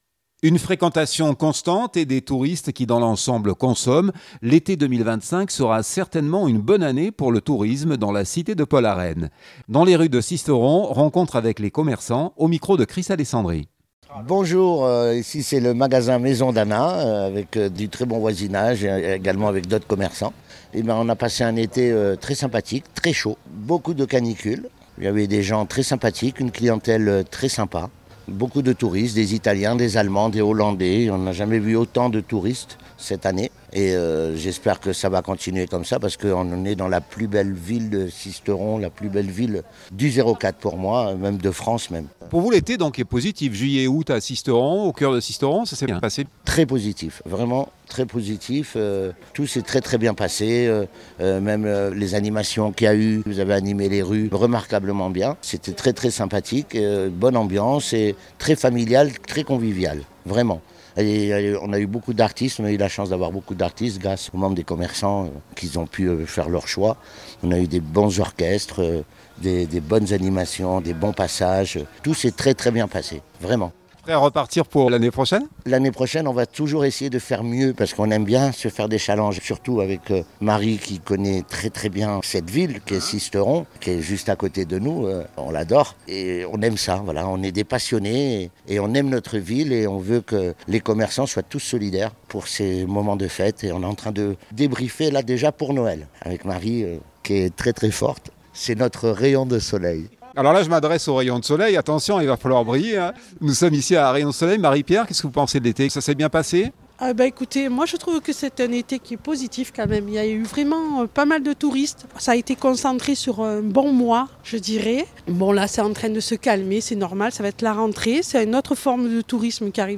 Les boutiques ont vu une belle fréquentation estivale. Dans les rues de Sisteron Rencontre avec les commerçants